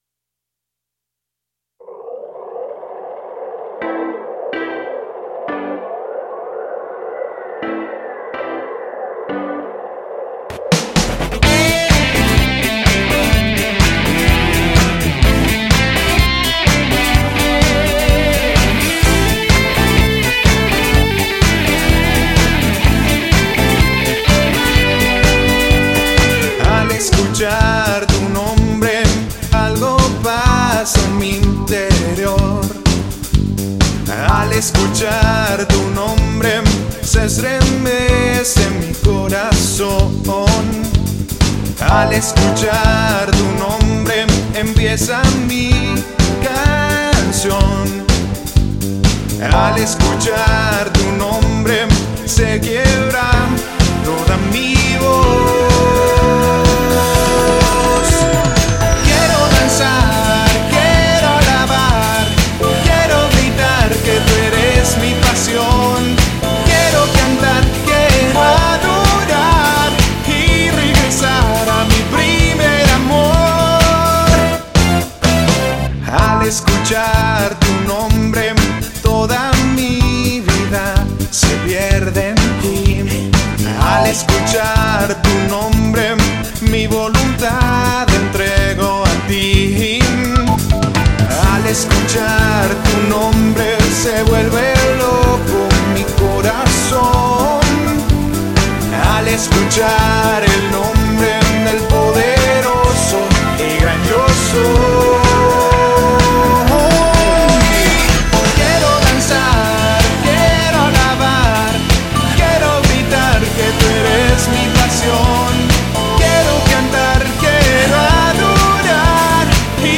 THIS IS A LATIN MUSICAL CD
Religious